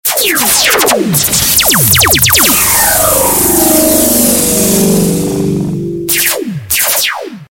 Kategorie Efekty Dźwiękowe